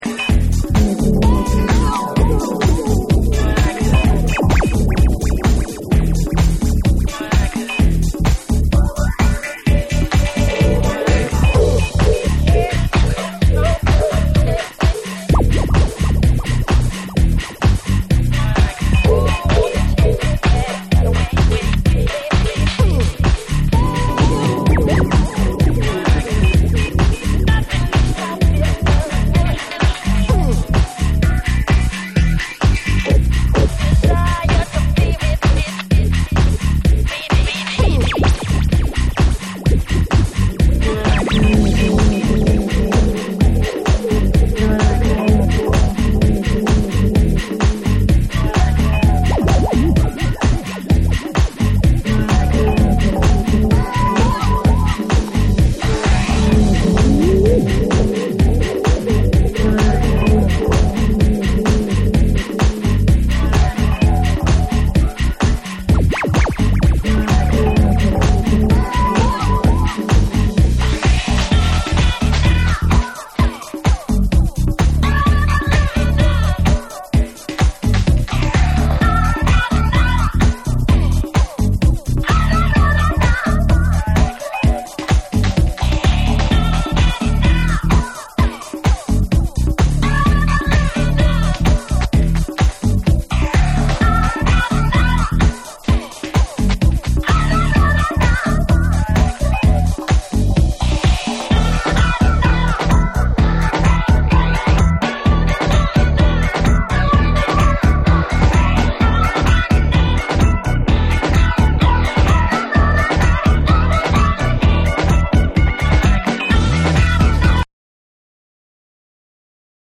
TECHNO & HOUSE / RE-EDIT / MASH UP